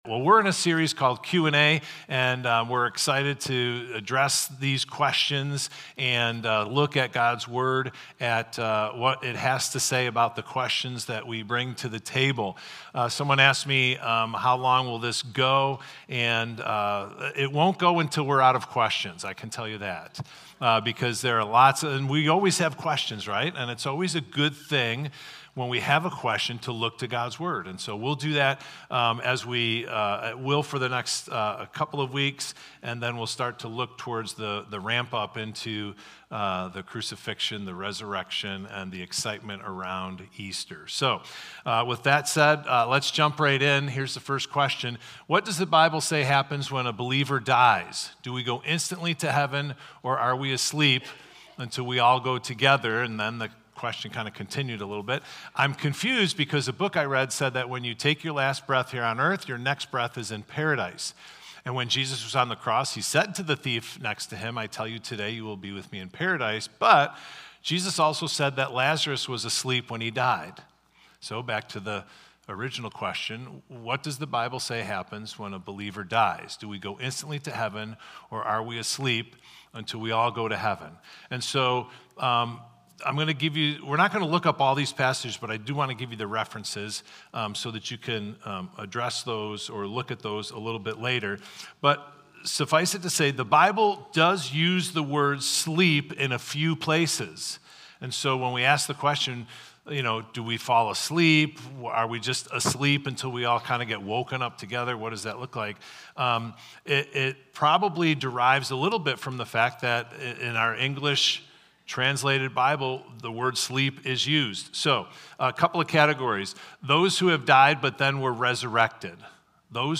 Victor Community Church Sunday Messages / How Do I Reach Others Who Struggle with Organized Religion?